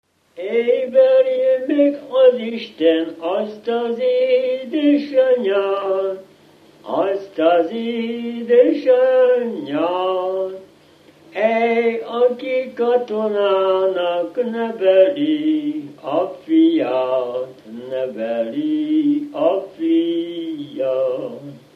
Felföld - Gömör és Kishont vm. - Csucsom
Stílus: 1.1. Ereszkedő kvintváltó pentaton dallamok
Kadencia: 8 5 (5) 4 1 1